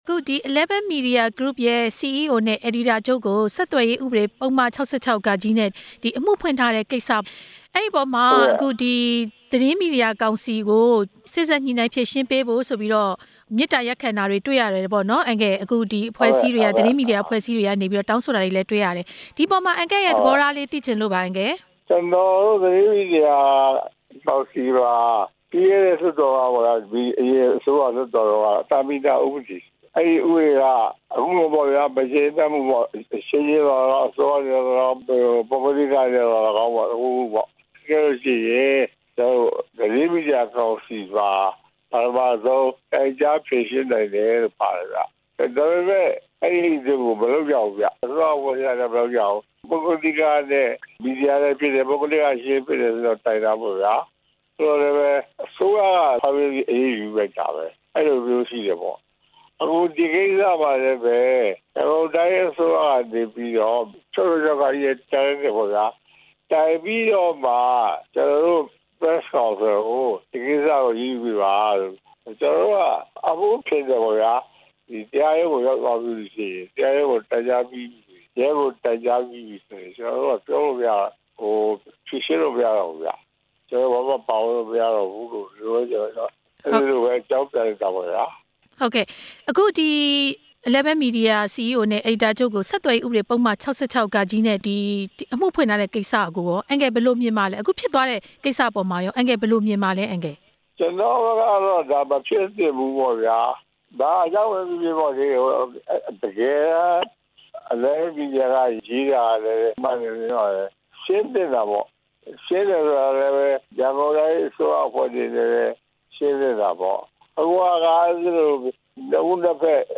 ဦးဖြိုးမင်းသိန်းနဲ့ Eleven မီဒီ ယာအမှု သတင်းမီဒီယာကောင်စီ ဥက္ကဌနဲ့ မေးမြန်းချက်